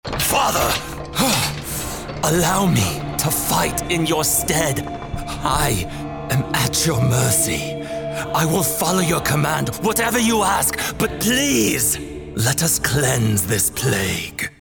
Video Games
Soprano